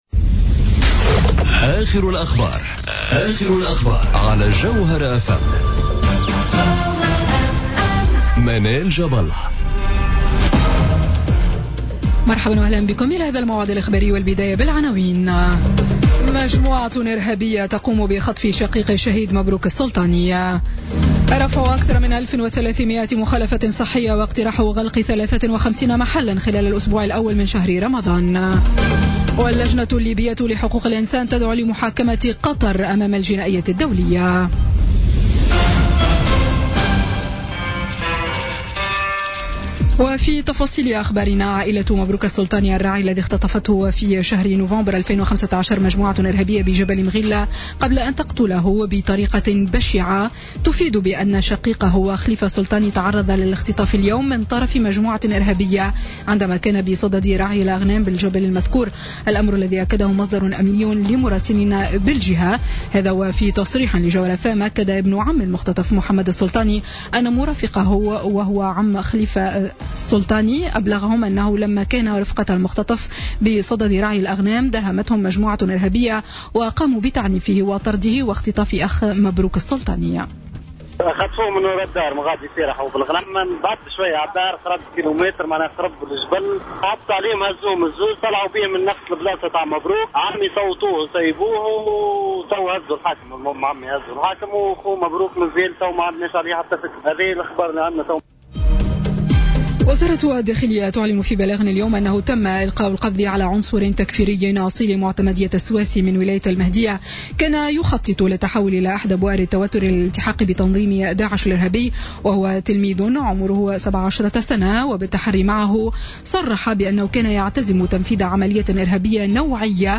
نشرة أخبار السابعة مساء ليوم الجمعة 2 جوان 2017